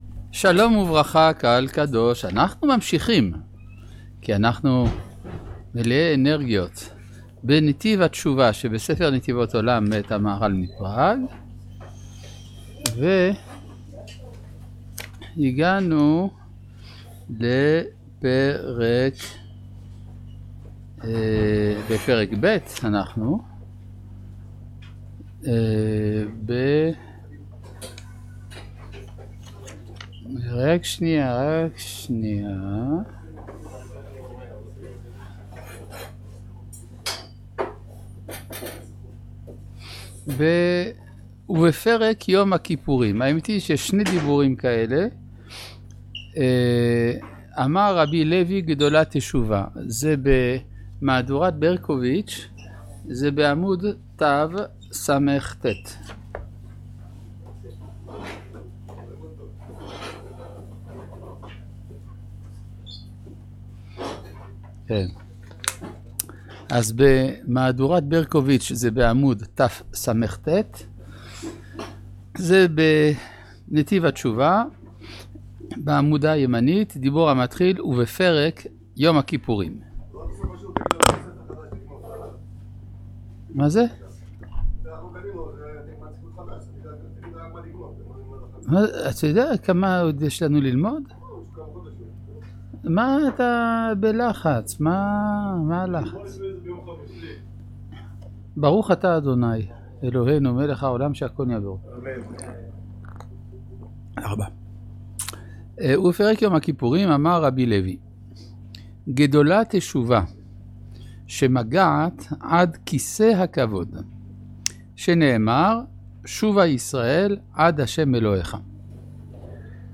לימוד ספר נתיבות עולם למהר"ל מפראג